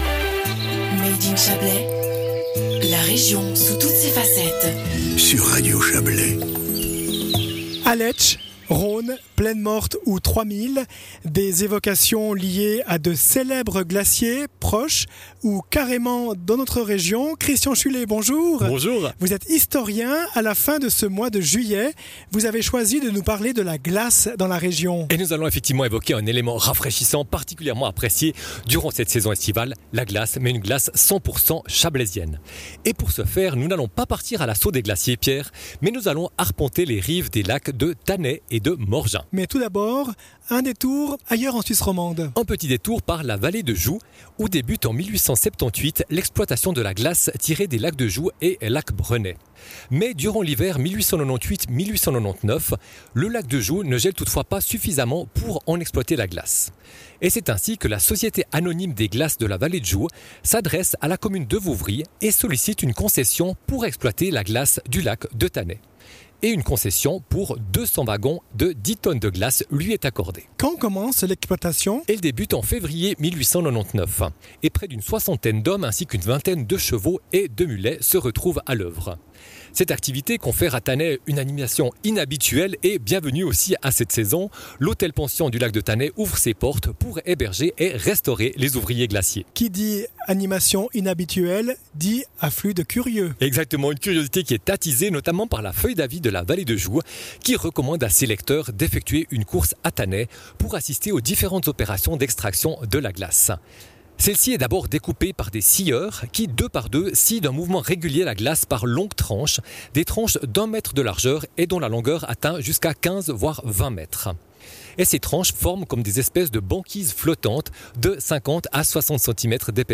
historien